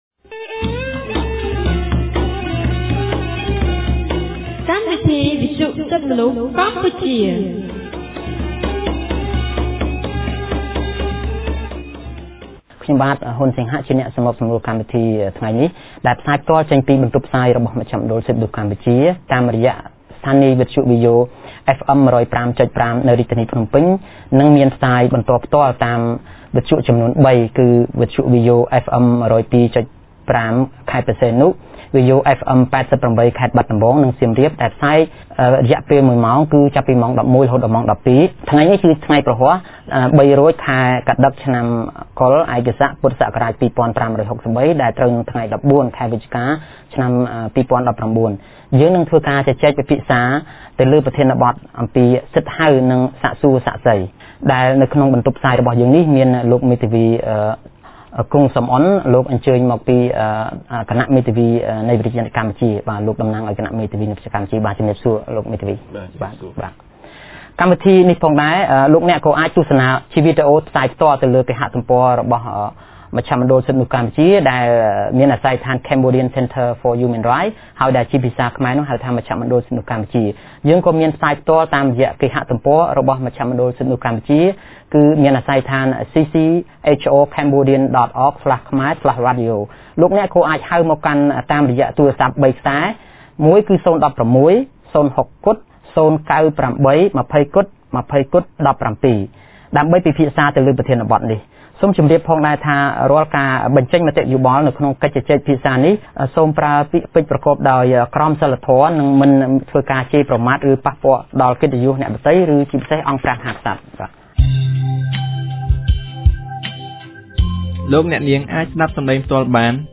ថ្ងៃសុក្រ ទី១៤ ខែវិច្ឆិការ ឆ្នាំ២០១៩ គម្រាងសិទ្ធិទទួលបានការជំនុំជម្រះដោយយុត្តិធម៌នៃមជ្ឈមណ្ឌលសិទ្ធិមនុស្សកម្ពុជា បានរៀបចំកម្មវិធីវិទ្យុក្រោមប្រធានបទស្តីពី សិទ្ធិហៅ និងសាកសួរសាក្សី។